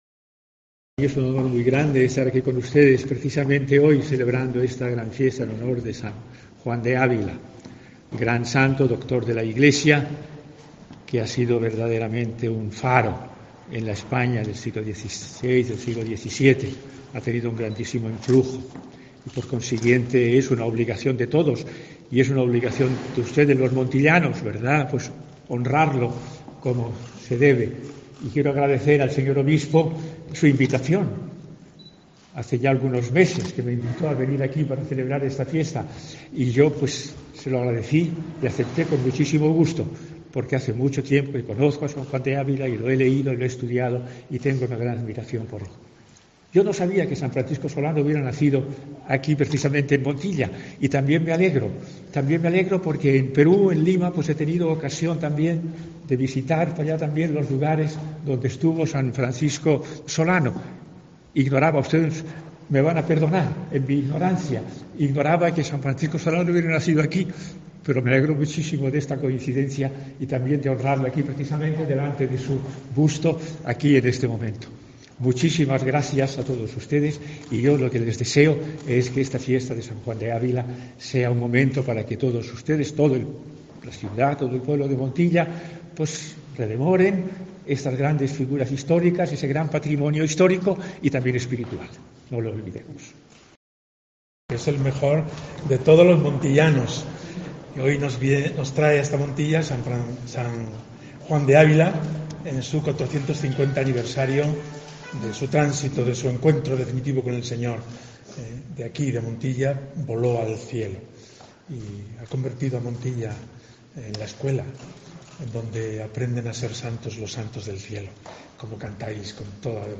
Escucha al Cardenal Luis Francisco Ladaria y al Obispo de Córdoba, Monseñor Demetrio Fernández